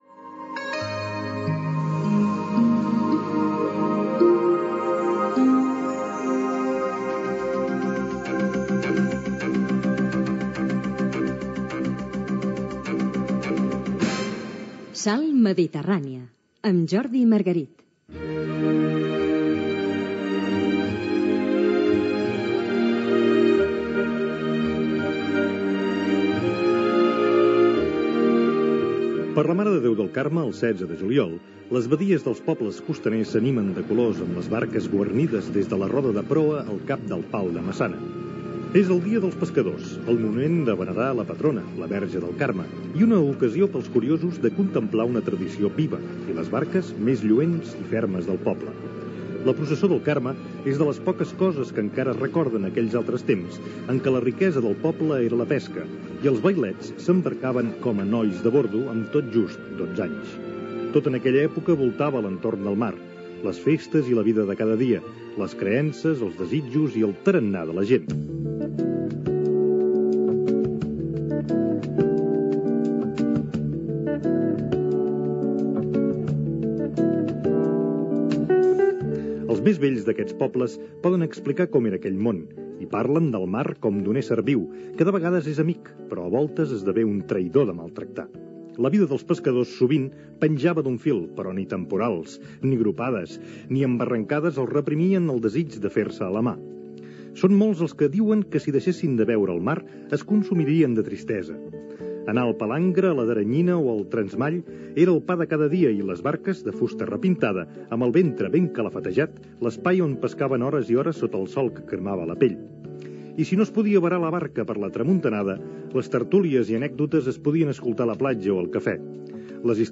Els pescadors amb la participació de Telm Zaragoza, alcalde de Tossa de Mar
Entreteniment